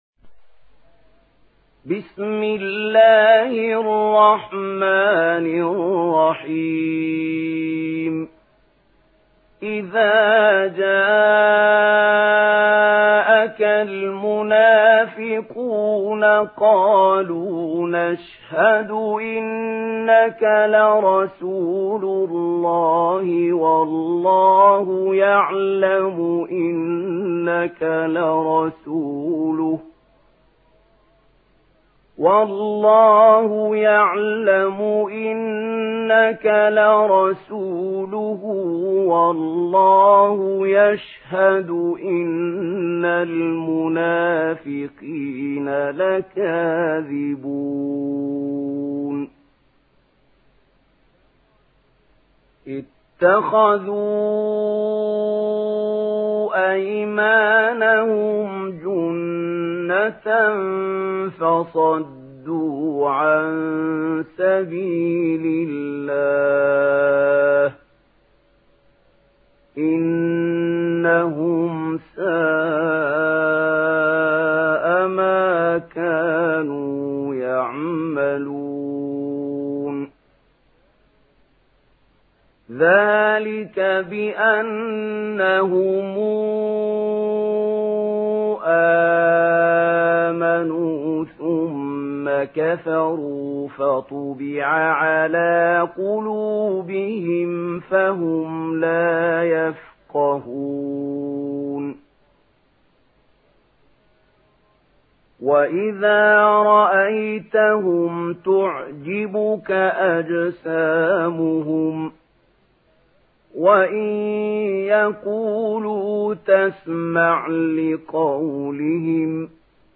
Surah المنافقون MP3 in the Voice of محمود خليل الحصري in ورش Narration
Listen and download the full recitation in MP3 format via direct and fast links in multiple qualities to your mobile phone.
مرتل ورش عن نافع